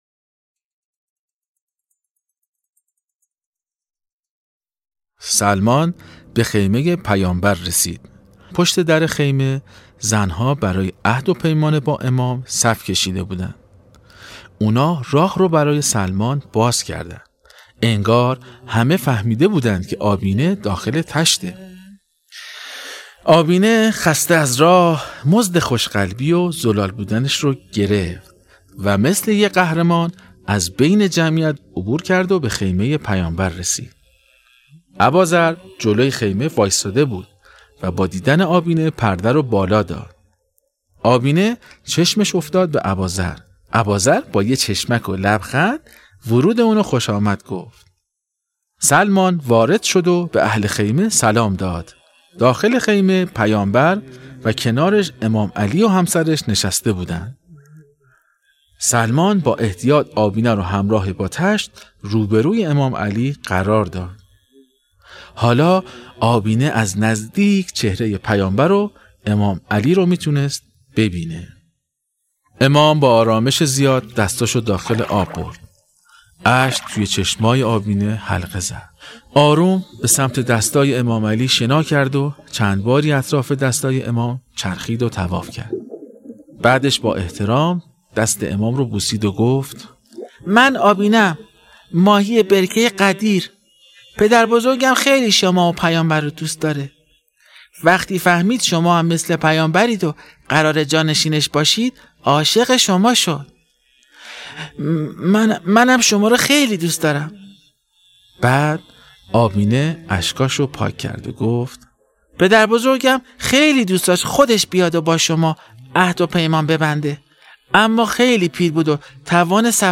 داستان ماهی ای که از یه جریان خیلی مهم برامون صحبت میکنه. باهم قسمت هشتم از داستان صوتی آبینه رو بشنویم.